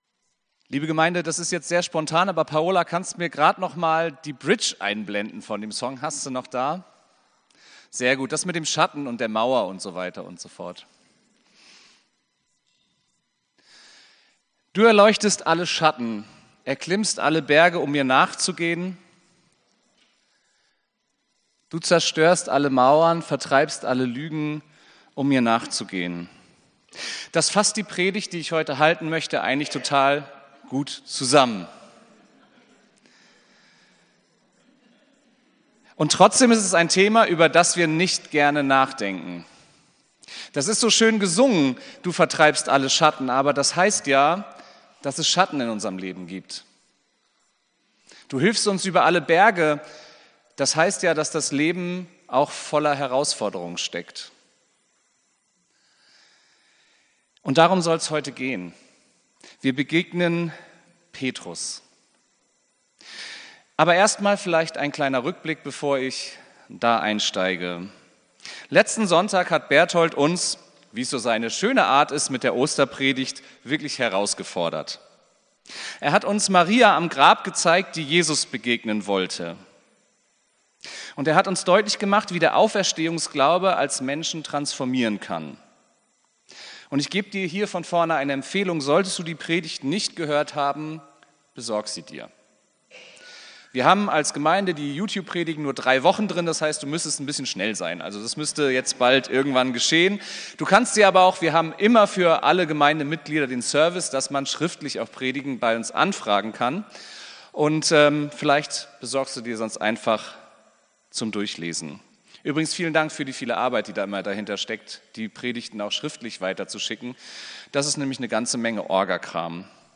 Predigt vom 07.04.2024